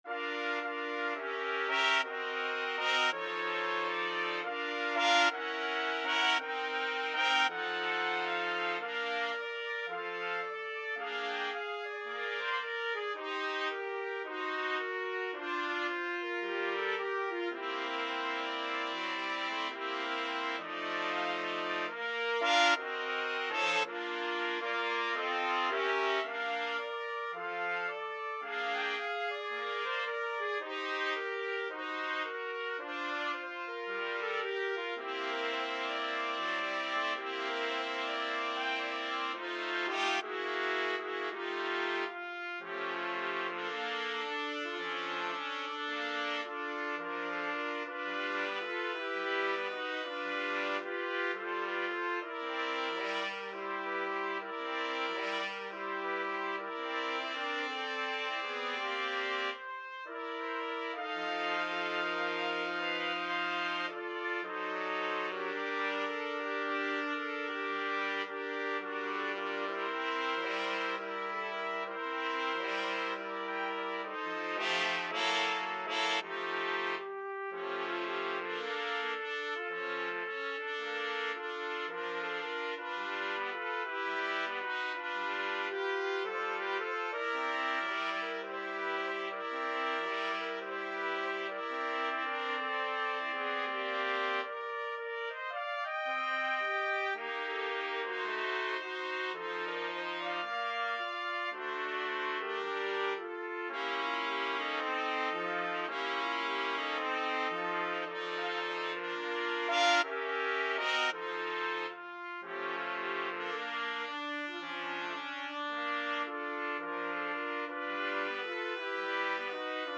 2/2 (View more 2/2 Music)
~ = 110 Moderate swing
Pop (View more Pop Trumpet Quartet Music)